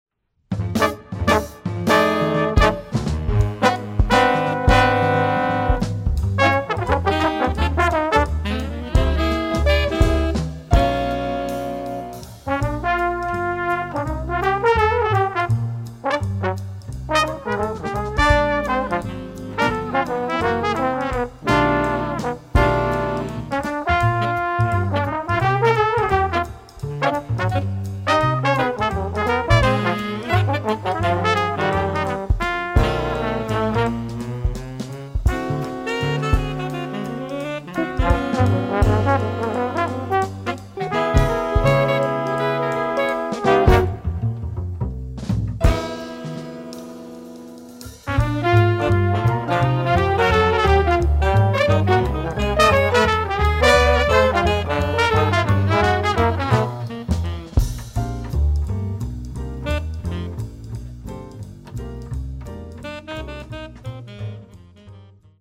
Jazz Nonet: